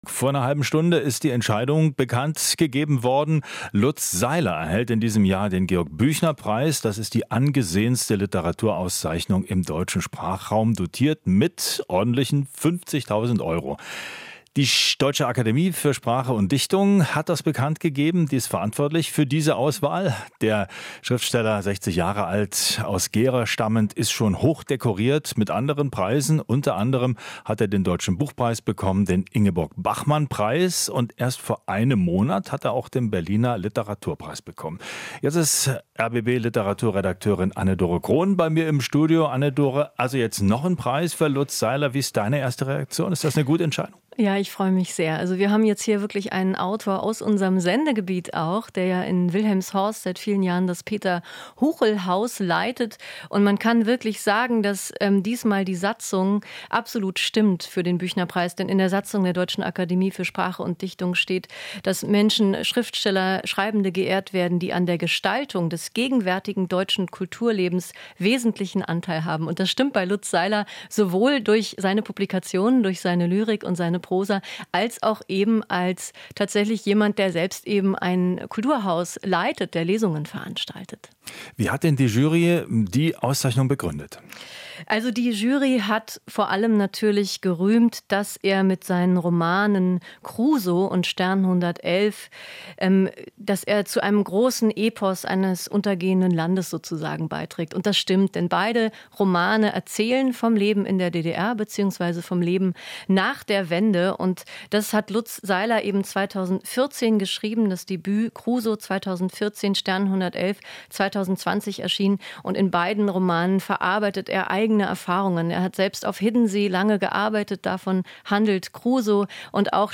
Interview - Lutz Seiler ist der Büchnerpreisträger 2023